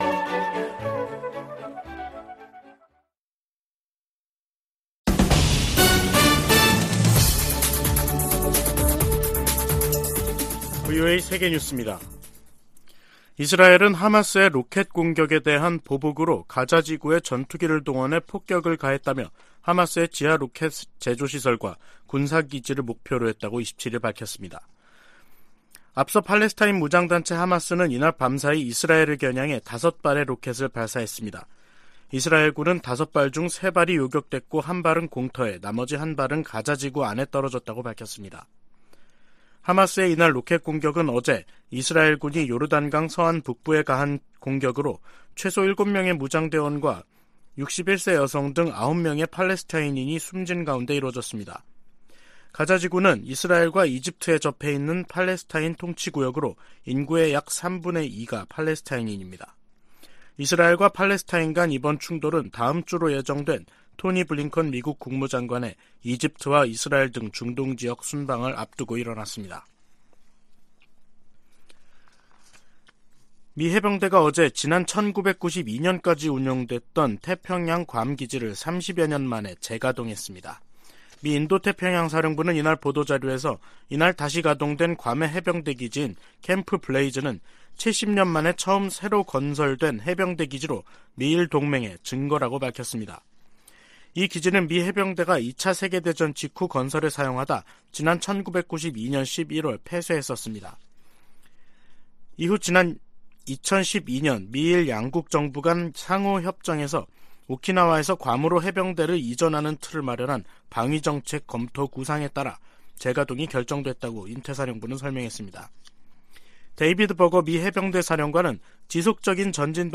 VOA 한국어 간판 뉴스 프로그램 '뉴스 투데이', 2023년 1월 27일 2부 방송입니다. 미국과 한국의 국방장관들이 31일 서울에서 회담을 갖고 대북정책 공조, 미국 확장억제 실행력 강화 등 다양한 동맹 현안들을 논의합니다. 미국 정부가 러시아 군사조직 바그너 그룹을 국제 범죄조직으로 지목하고 현행 제재를 강화했습니다.